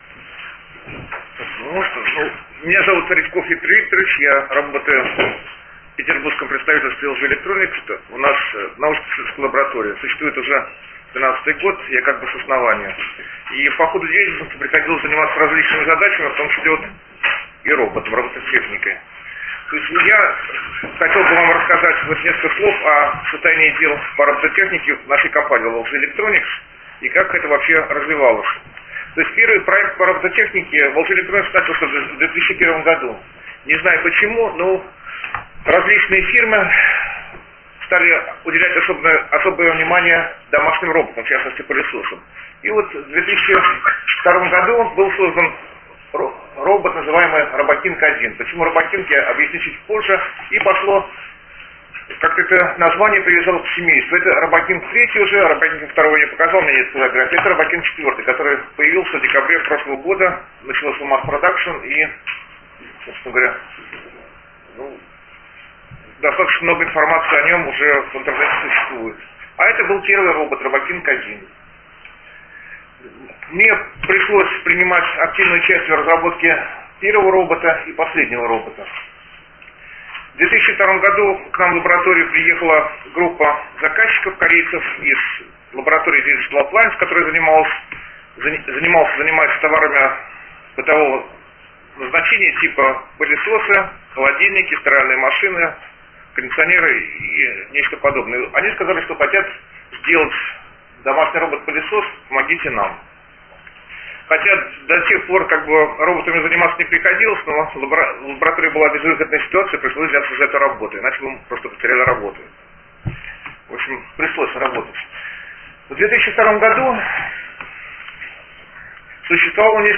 Аудиозапись доклада. hot!